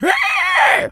pgs/Assets/Audio/Animal_Impersonations/pig_scream_short_02.wav at master
pig_scream_short_02.wav